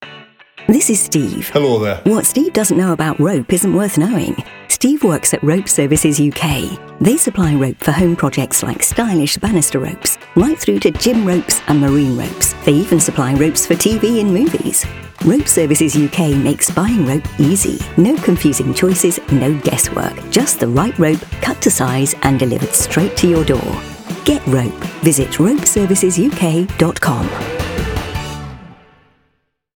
Whether you’re in the workshop, on the school run, or just having a scroll, keep your ears open for our friendly new voice sharing what we’re all about.
radio-advert-ropeservices-uk.mp3